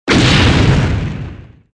impact_torpedo.wav